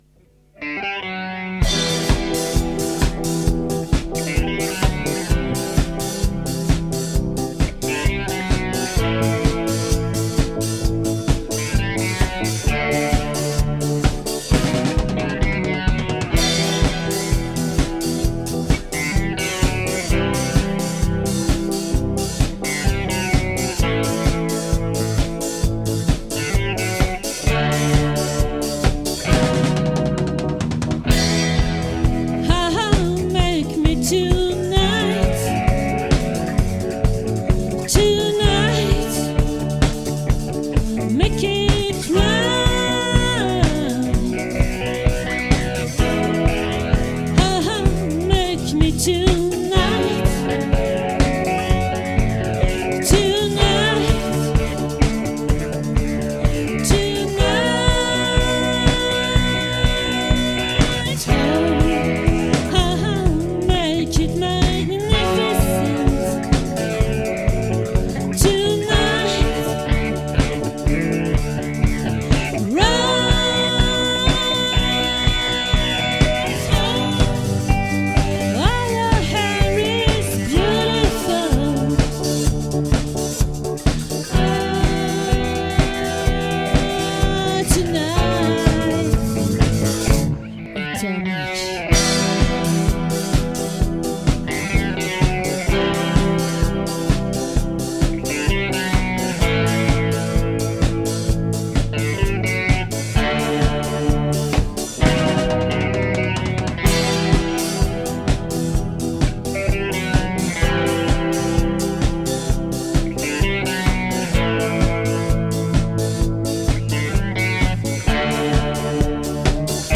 🏠 Accueil Repetitions Records_2022_10_05_OLVRE